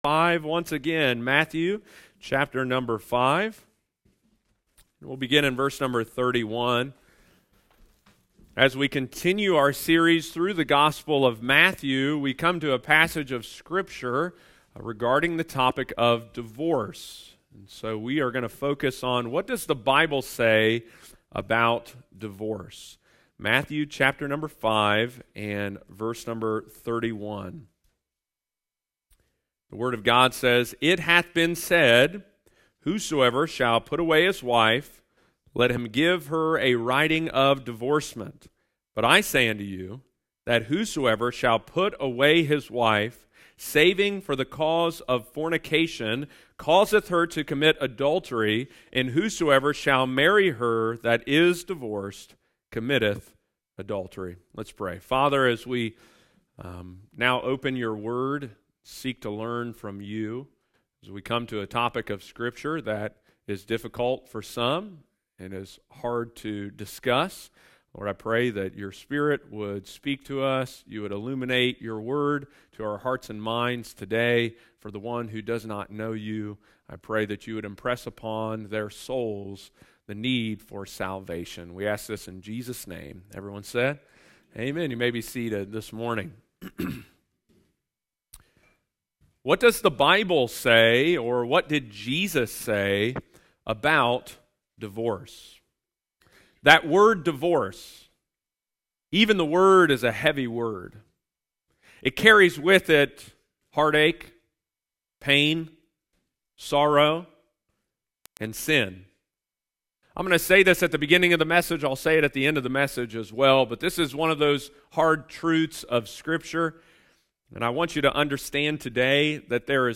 – Lighthouse Baptist Church, Circleville Ohio